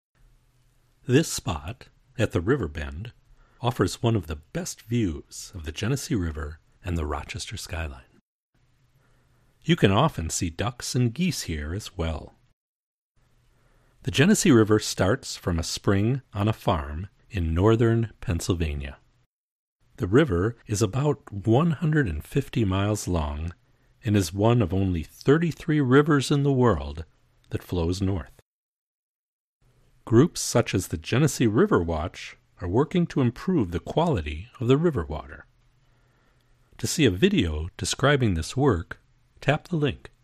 This narrated walking tour begins near the Ford Street Bridge, and continues along the west bank to a civil war camp and historic railroad bridge.